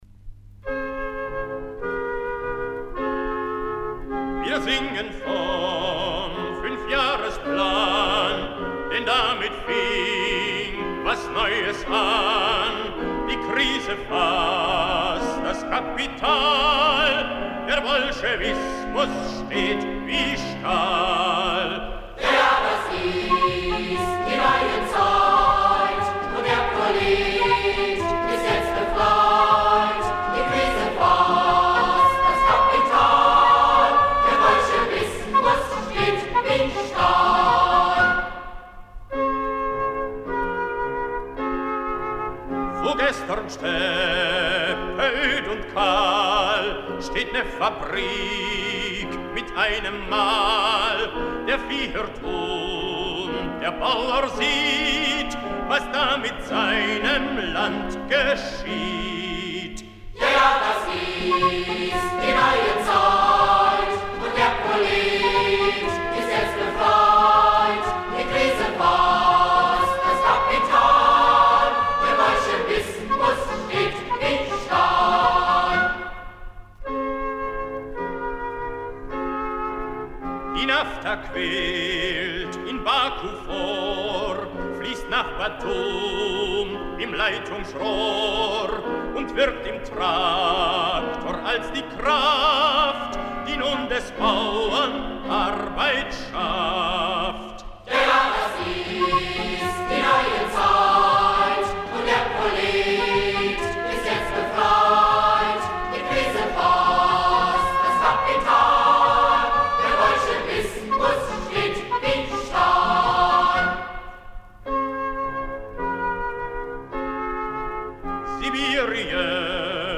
Instrumentalgruppe